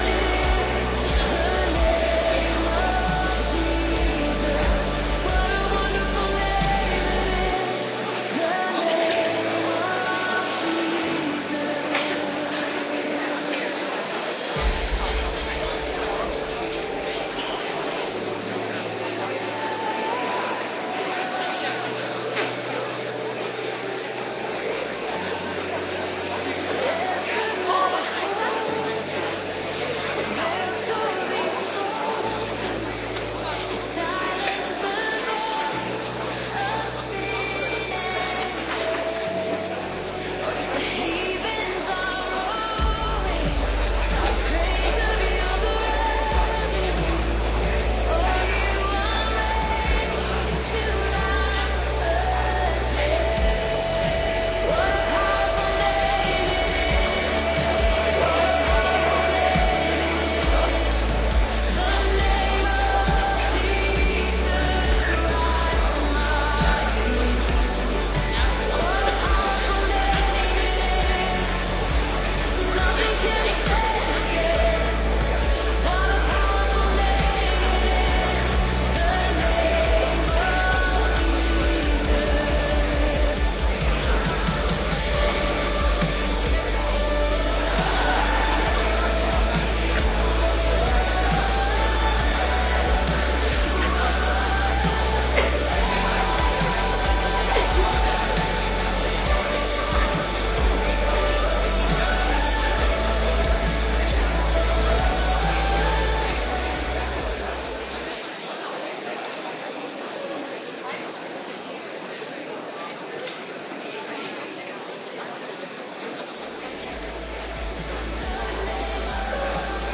Kerkdiensten